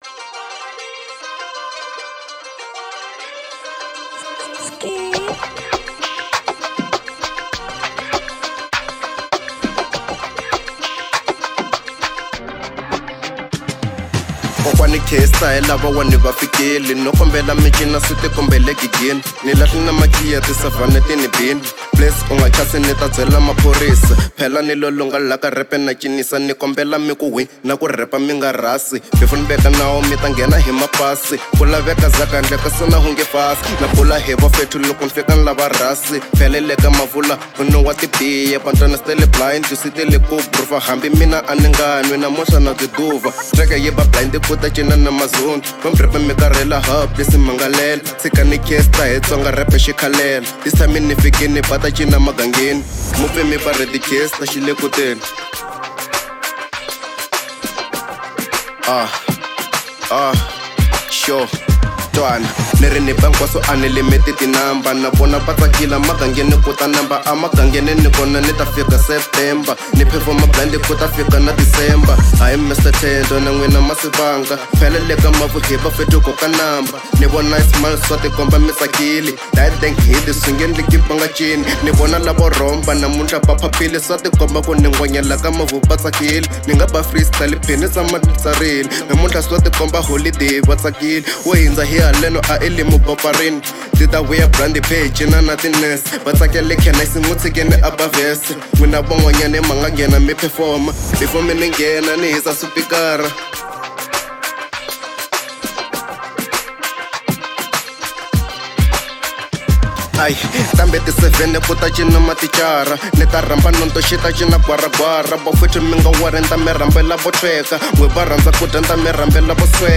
02:42 Genre : Hip Hop Size